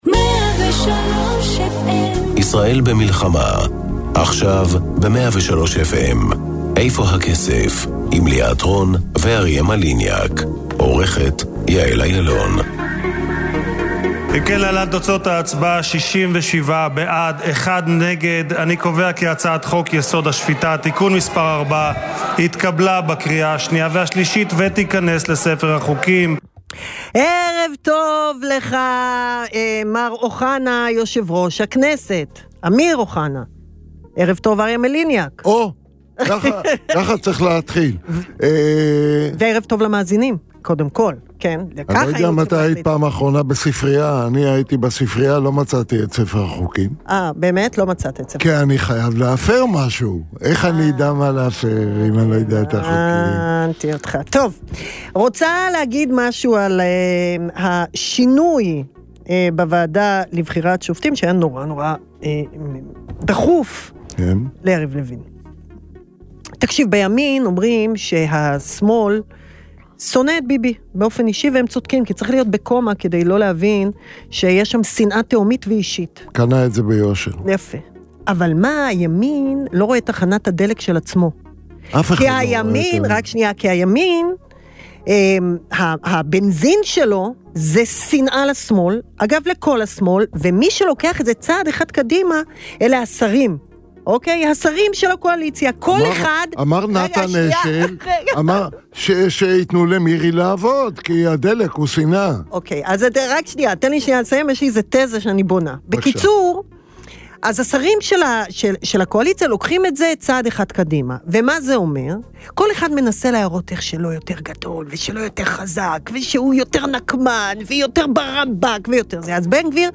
לצד הכותרות הכלכליות, מביאה התוכנית ראיונות עם בכירי המשק, תחקירי צרכנות פיננסית, טורים אישיים שתוקפים את נושאי הכלכלה מזוויות שונות, ופינות בנושאי טכנולוגיה ואפילו טיולים ופנאי.